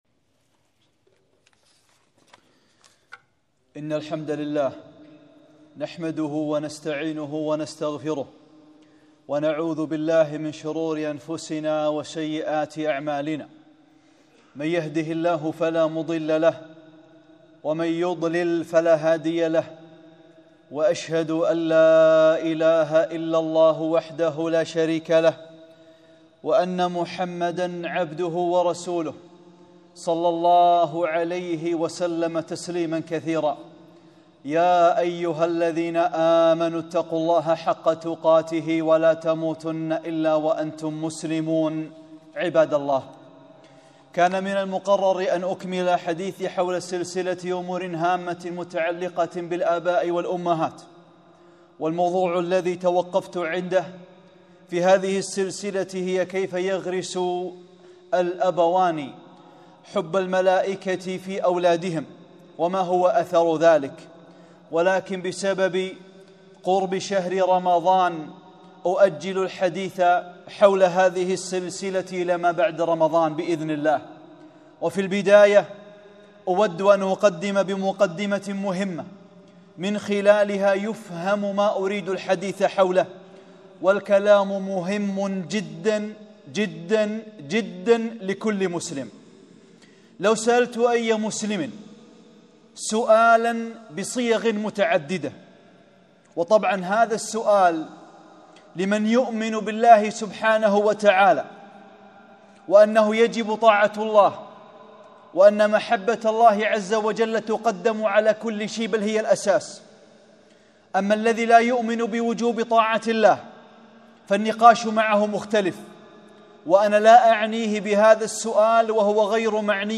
خطبة - لماذا نفرح بقدوم رمضان؟